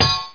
staffhitwall.mp3